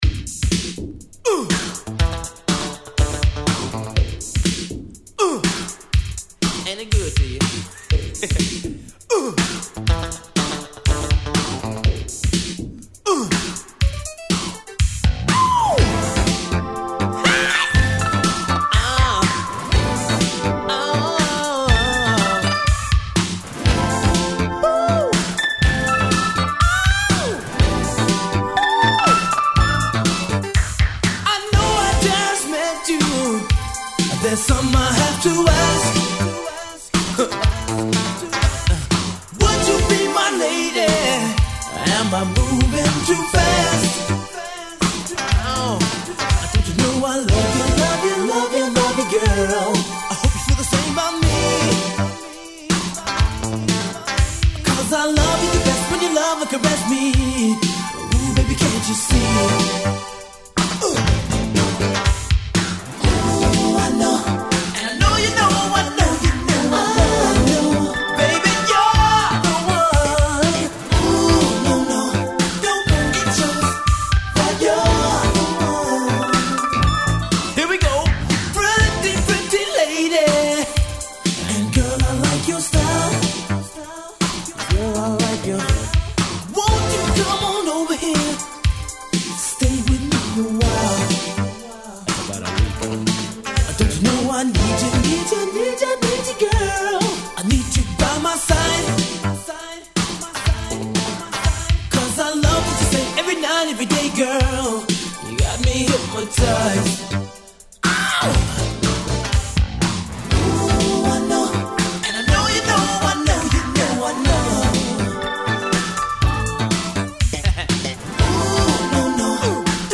・ SOUL / FUNK LP